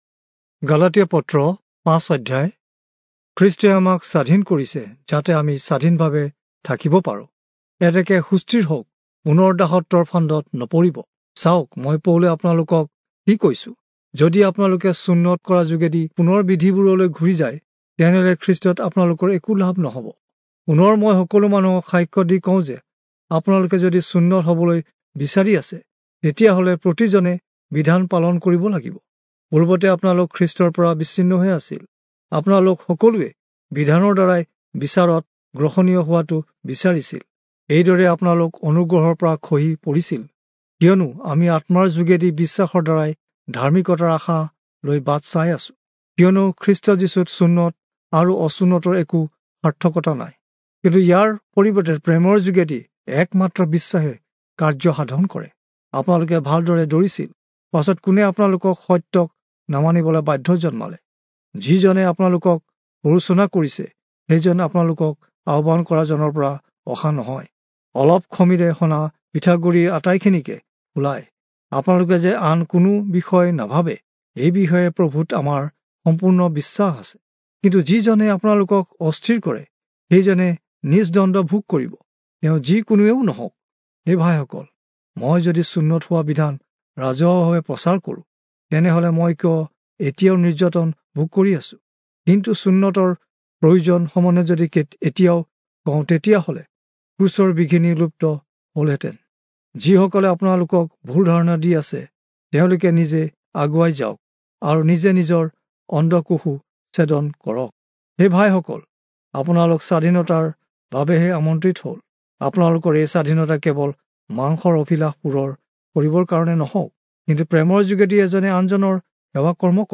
Assamese Audio Bible - Galatians 1 in Bnv bible version